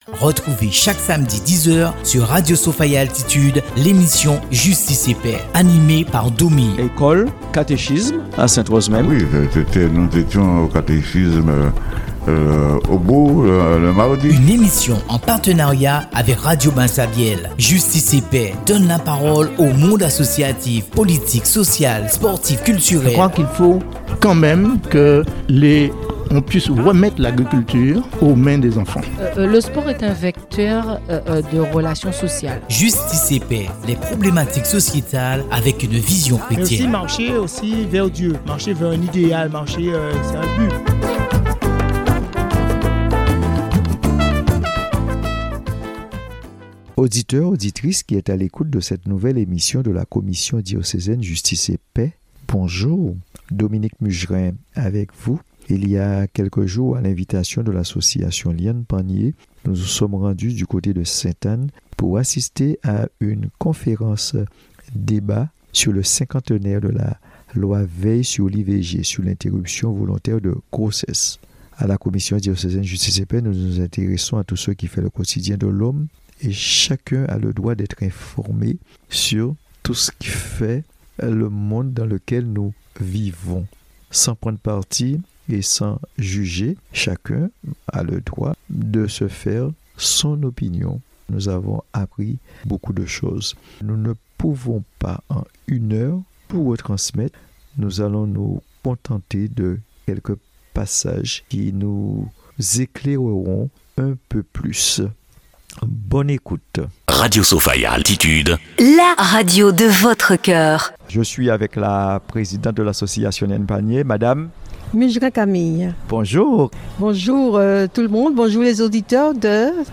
L'association Lyann panyé de Sainte-Anne a organisé une conférence-débat à l'occasion du 50ème anniversaire de la loi VEIL.